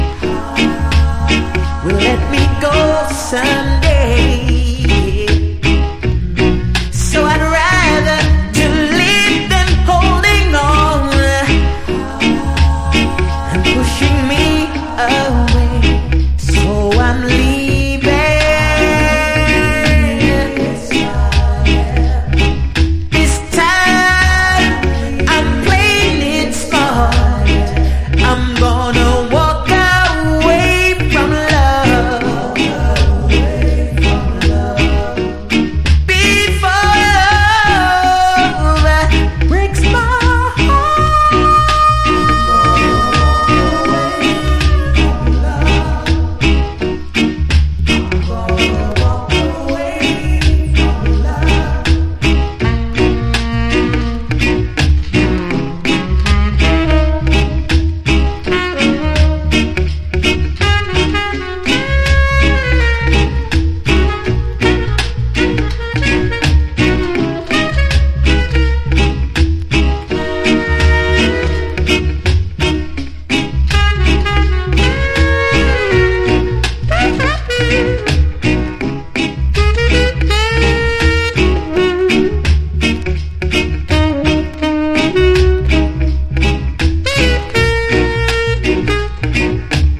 UKラヴァーズ・クラシック！